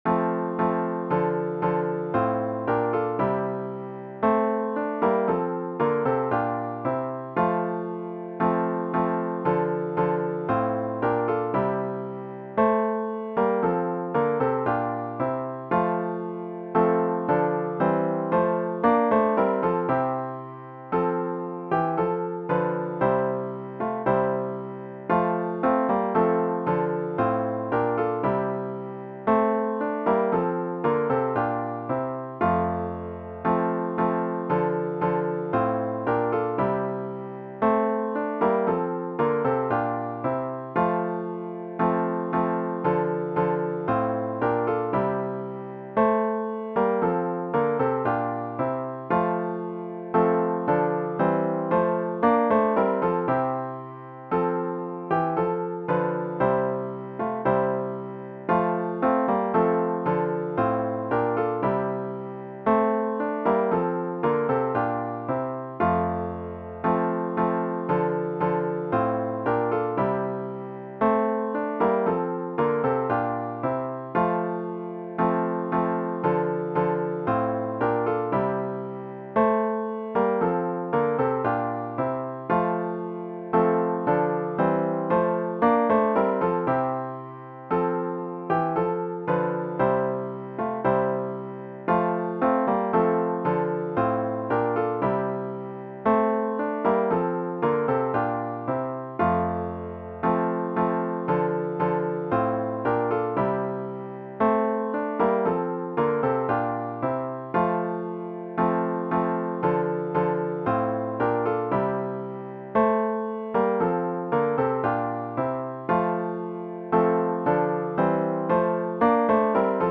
HYMN   “Christ the Lord Is Risen Today!”